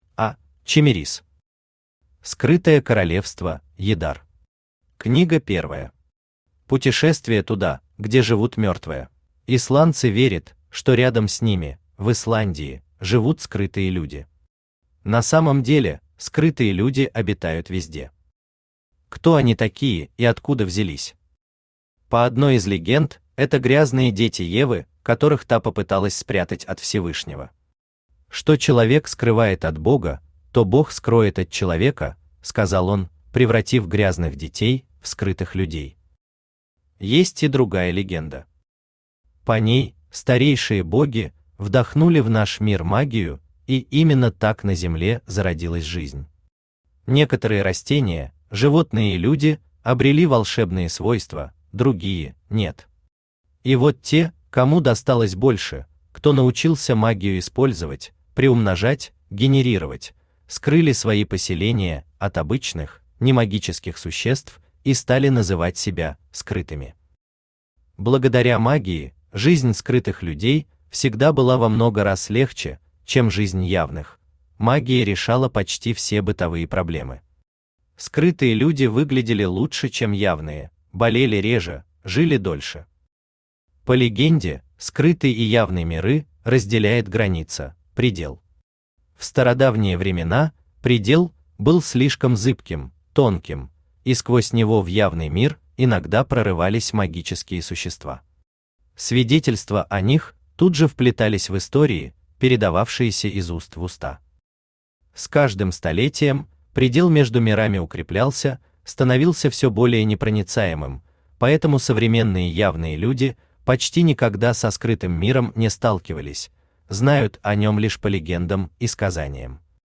Аудиокнига Скрытое королевство Ядар. Книга первая. Путешествие туда, где живут мертвые | Библиотека аудиокниг
Путешествие туда, где живут мертвые Автор А.Чемерис Читает аудиокнигу Авточтец ЛитРес.